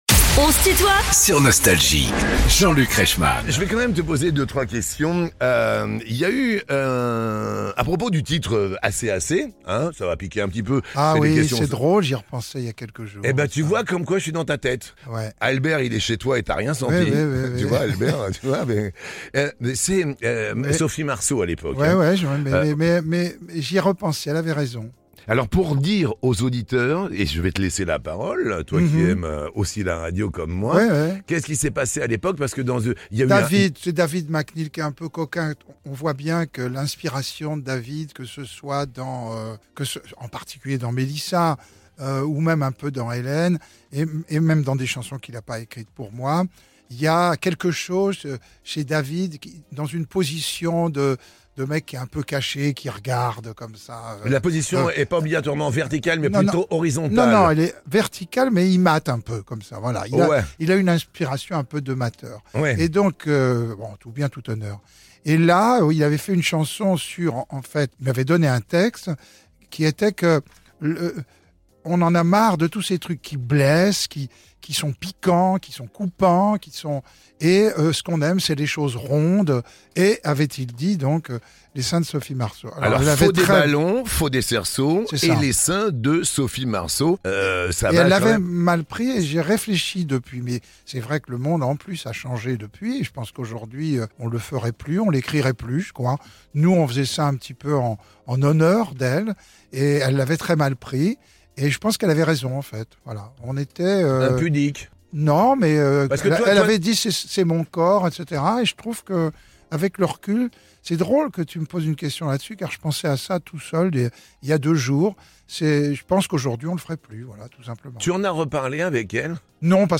Invité de "On se tutoie ?...", Julien Clerc répond aux questions sans filtre de Jean-Luc Reichmann ~ Les interviews Podcast